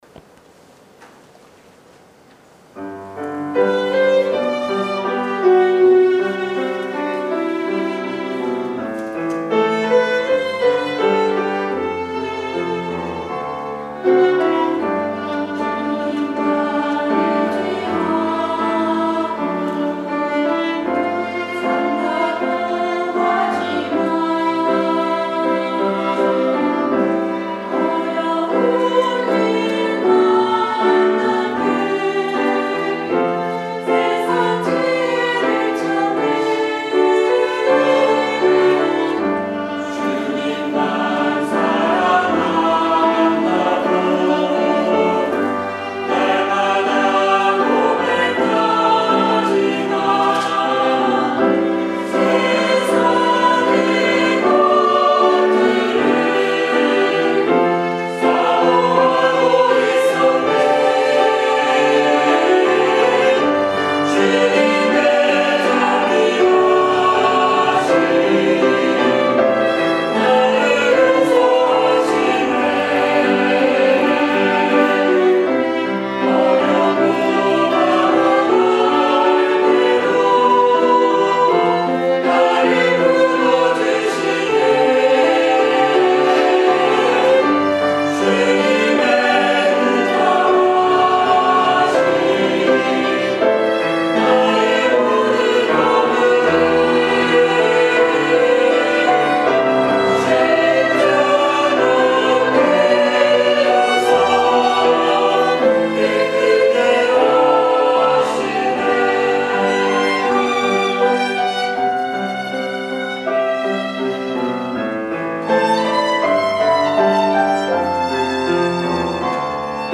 3월 1일 주일 예배 찬양